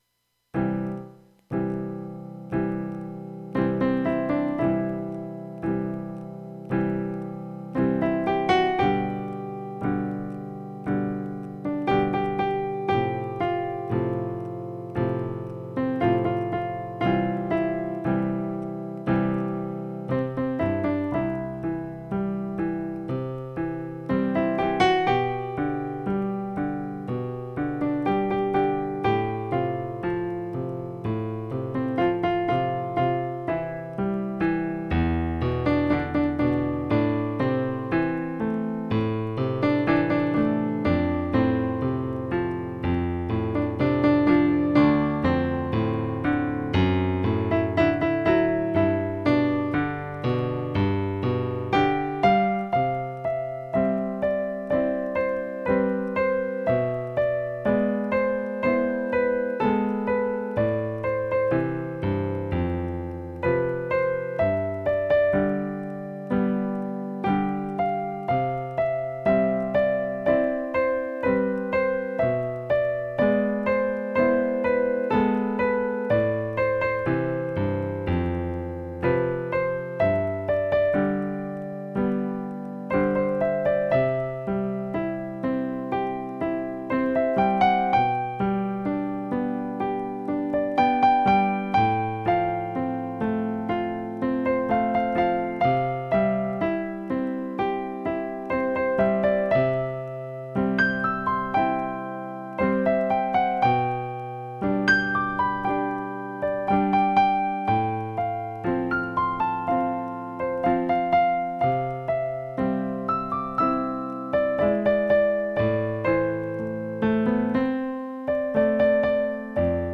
Partitura para piano / Piano score (pdf)